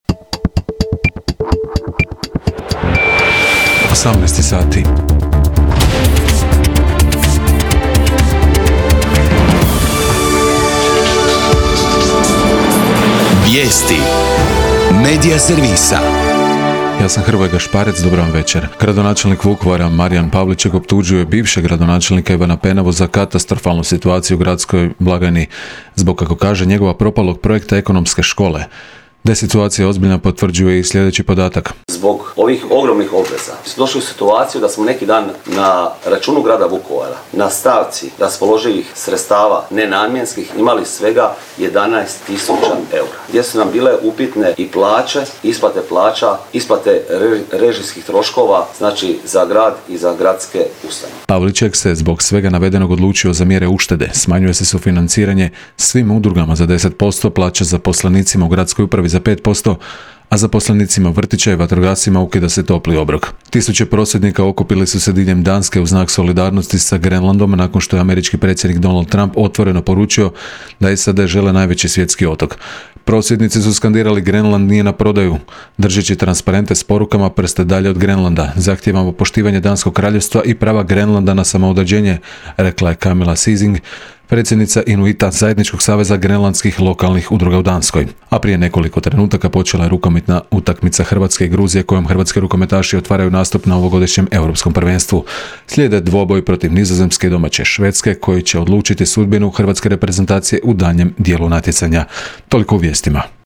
VIJESTI U 18